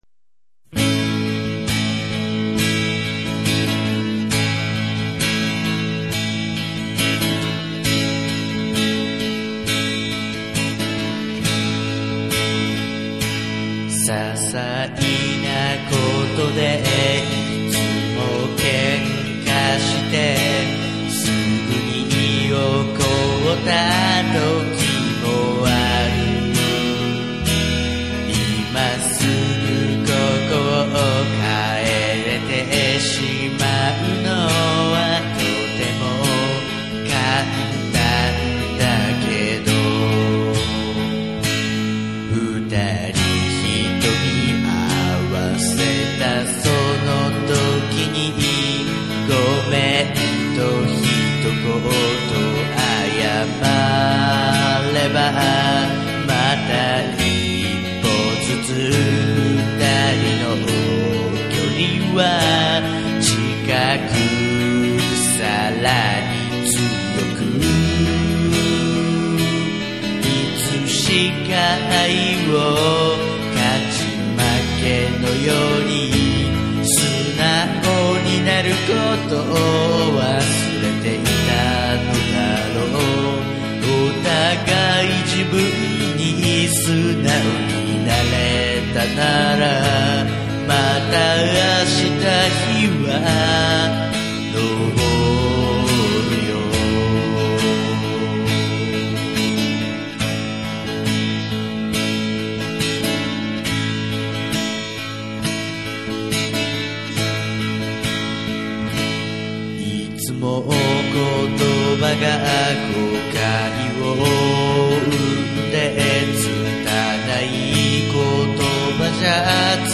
作詞、作曲、実演、録音：奥様レコード
ビンボーシステムで録ったわりには、けっこうシャらーんって音質が好きです。